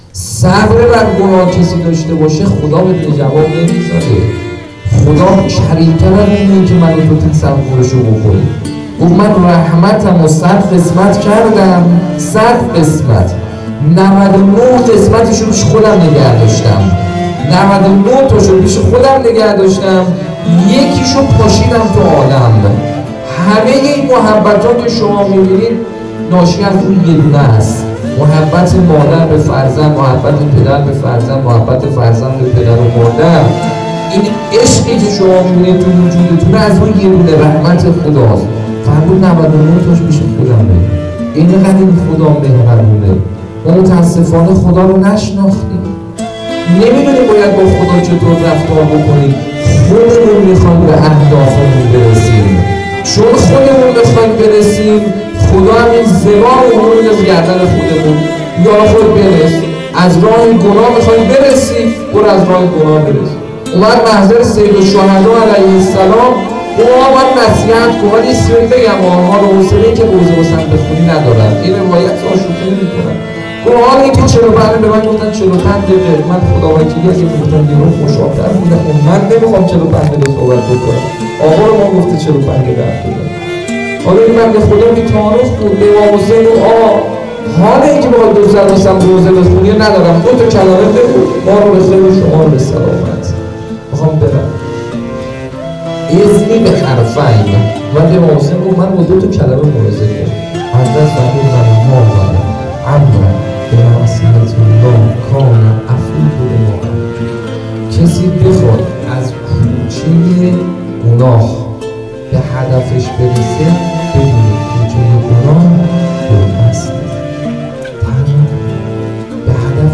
سخنرانی.wav